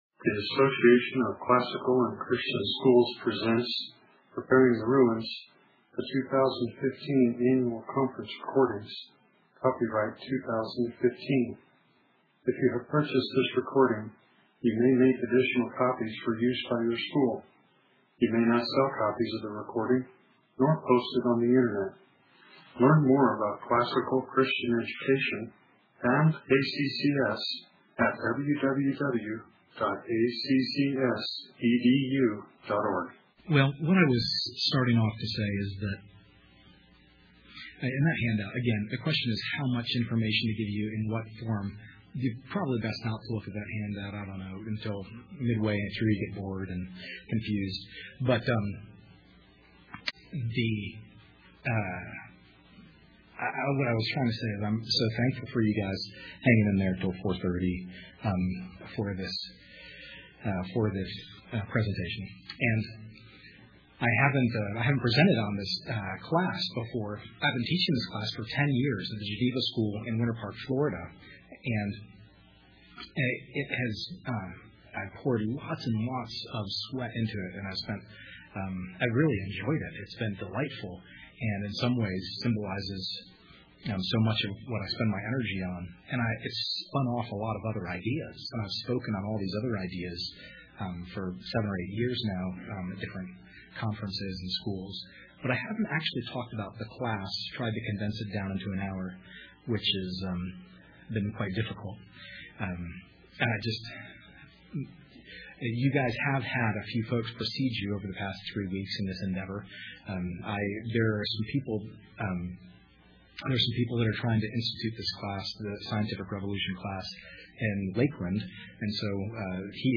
2015 Practicum Talk, 1:05:02, All Grade Levels, Math, Science
Additional Materials The Association of Classical & Christian Schools presents Repairing the Ruins, the ACCS annual conference, copyright ACCS.